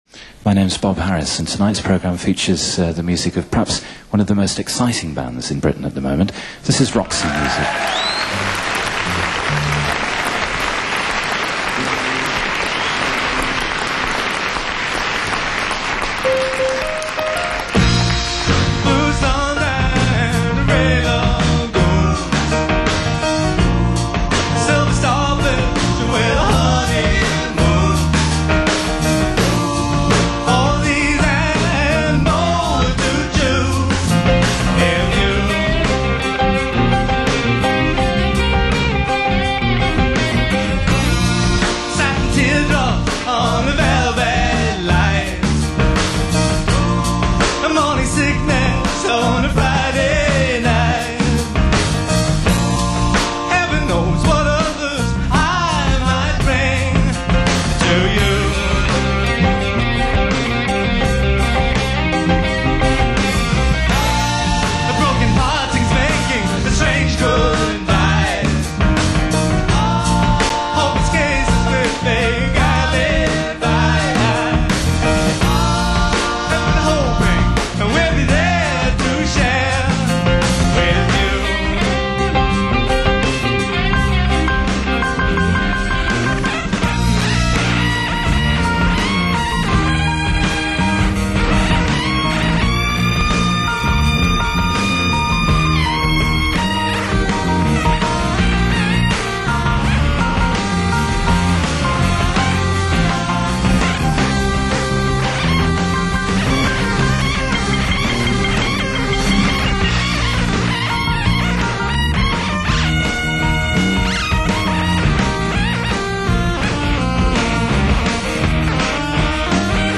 keyboards
soaring and fractured guitar solos